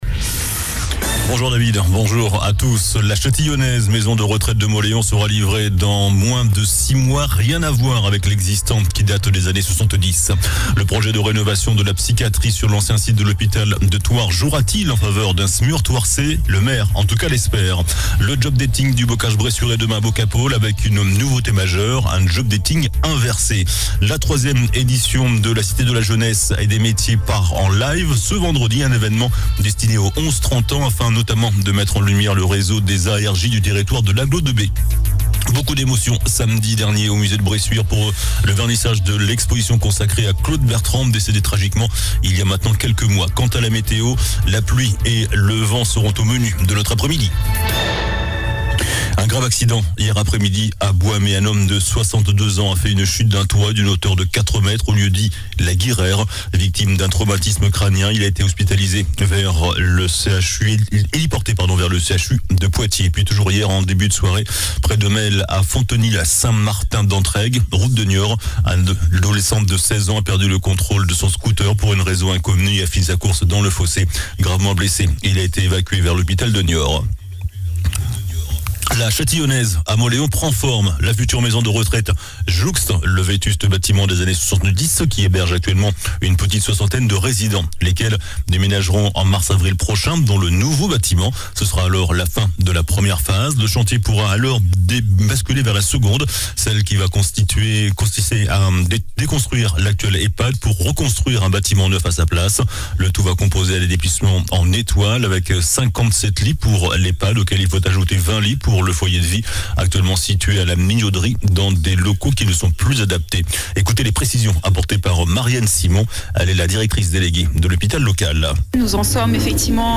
JOURNAL DU MERCREDI 18 OCTOBRE ( MIDI )